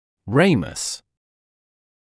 [‘reɪməs][‘рэймэс]ветвь (напр. нижней челюсти)